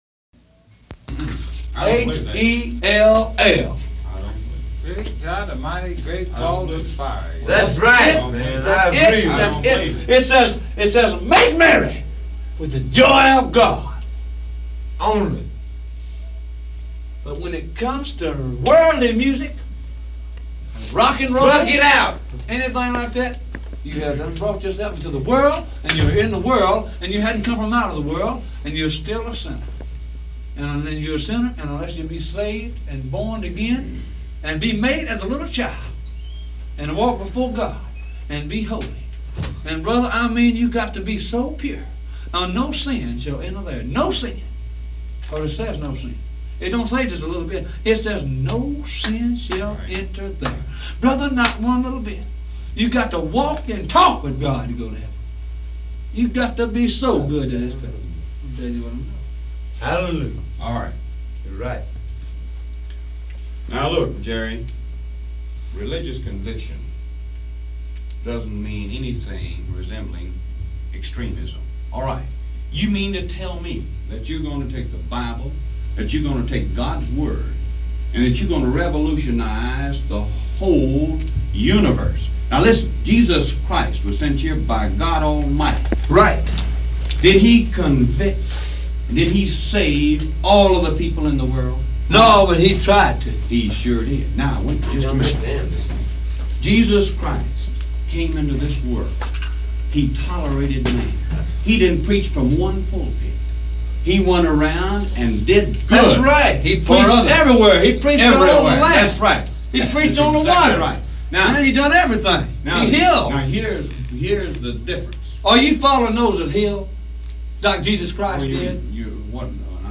The tape was rolling during the argument, so you may hear Lewis and Phillips discussing the song in the clip below:
Jerry Lee Lewis & Sam Phillips Argue
The argument continued in a dialogue that appears somewhat disjointed.
05-Jerry-Lewis-Sam-Phillips-Argue.mp3